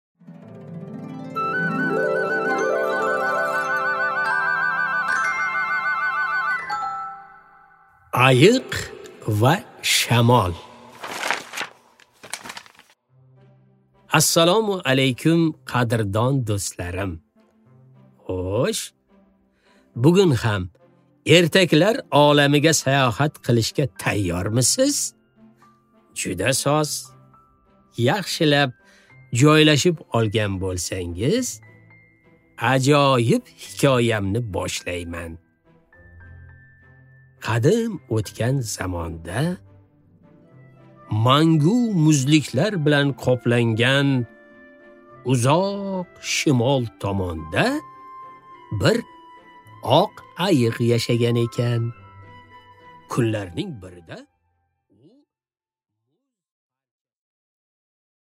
Аудиокнига Ayiq va shamol | Библиотека аудиокниг
Aудиокнига Ayiq va shamol Автор Народное творчество.